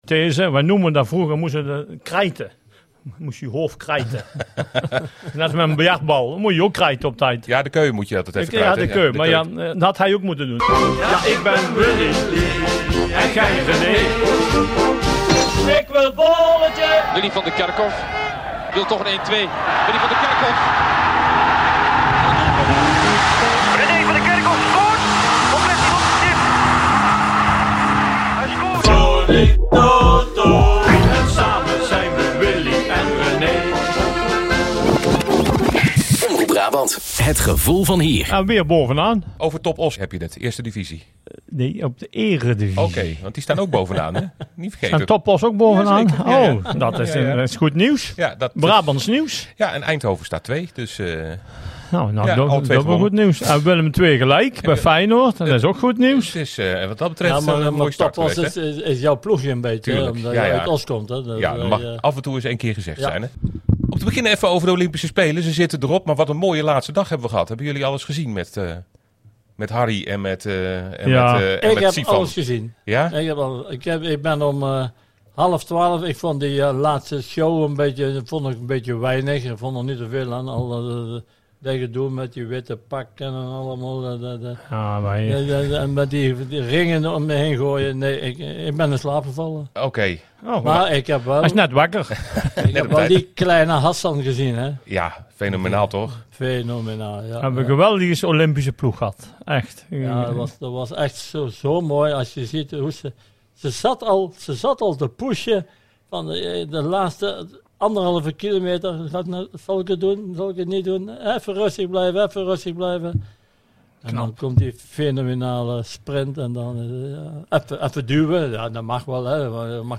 Luister naar Omroep Brabant regionaal nieuws, update donderdagochtend 14 september 2023 om 9 minuten over half zeven.